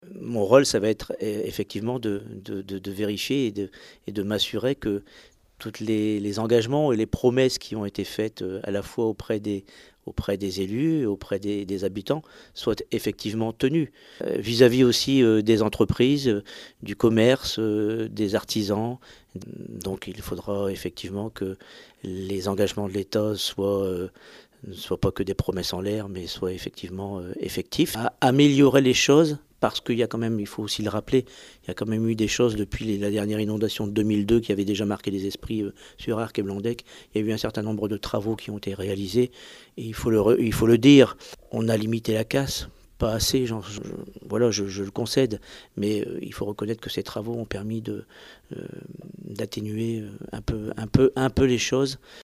HP ILLUSTRATION Nous avons rencontré  Bertrand PETIT Député de la 8° circonscription